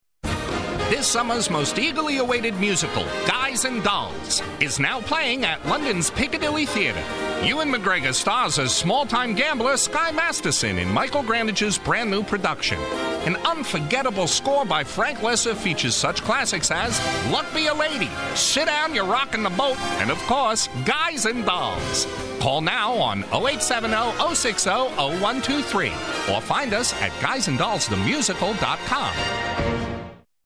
USA. Big voice all American sound. Movie promos, ads, documentaries.